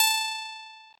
弹拨式吉他音符 " B1
描述：PluckedGuitarSingle注
标签： 弹拨 单注 吉他
声道立体声